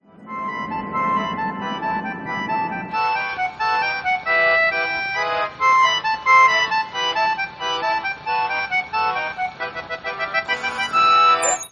L3.0 ambiance sonore.mp3 (92.04 Ko)